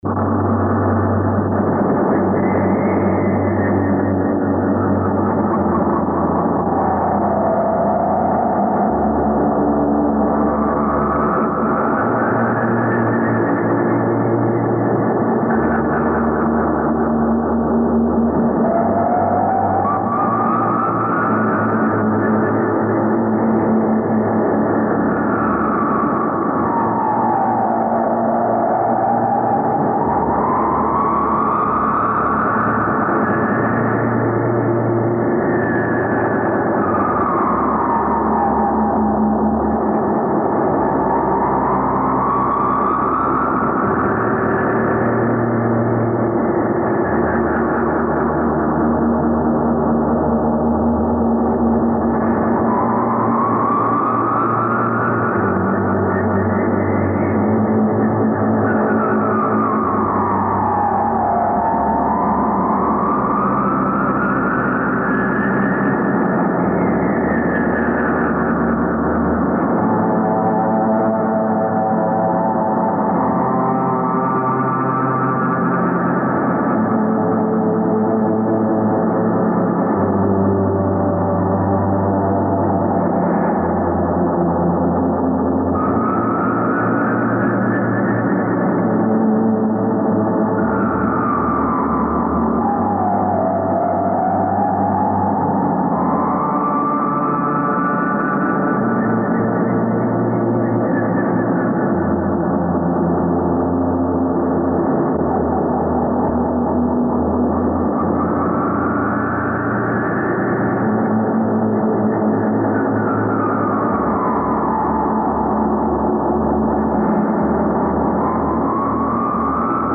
Two unreleased long tracks recorded in 1982.
Remastered from original master tapes.
This is Industrial Music.